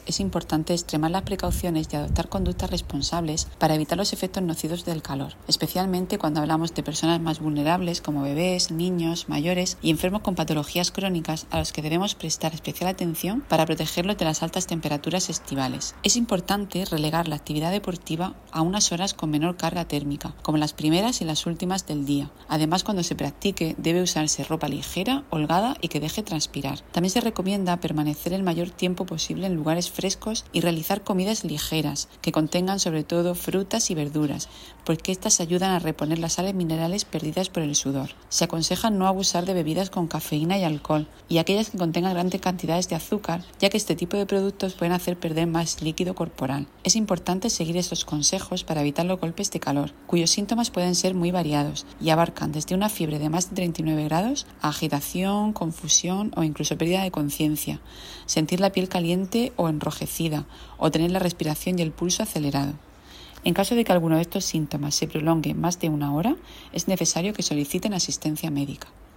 Sonido/ Declaraciones de la gerente del Servicio Murciano de Salud, Isabel Ayala, con consejos para prevenir los efectos nocivos del calor.